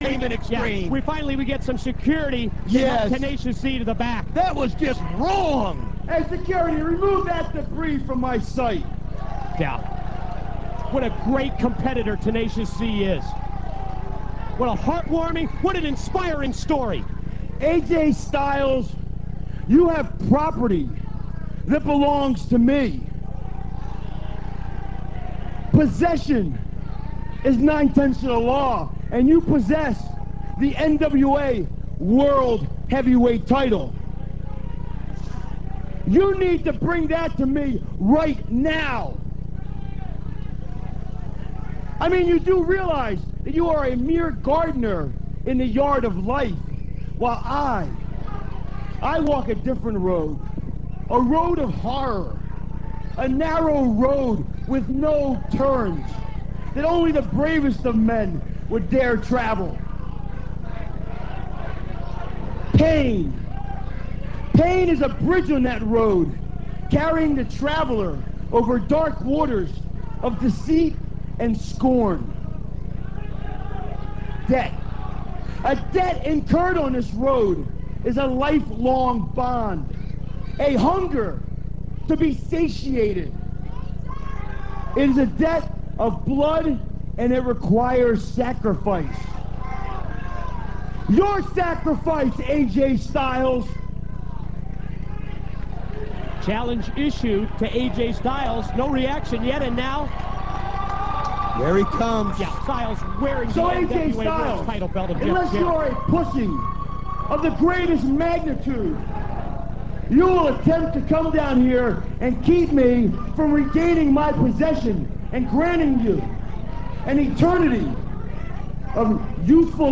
raven92.rm - This clip comes from NWA/TNA - [2.05.03]. Raven demands that A.J. Styles return the NWA Title he stole from Raven on 1/29, calling him an arrogant little pussy.